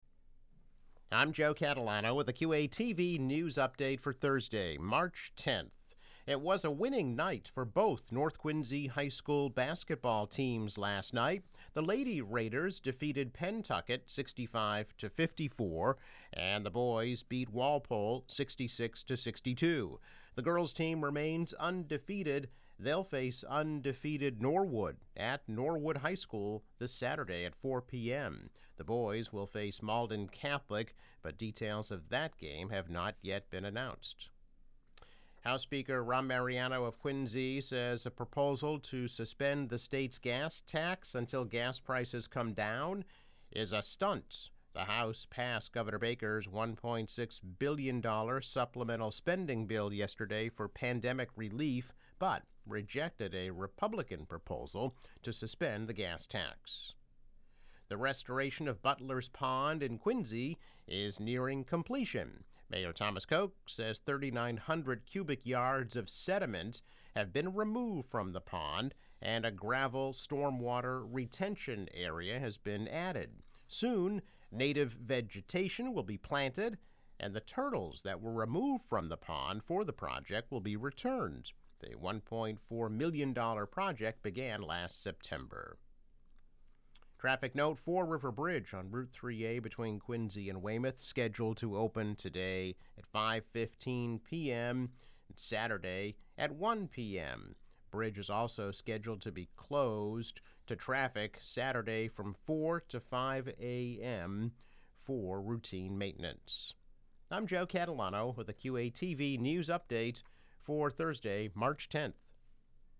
News Update - March 10, 2022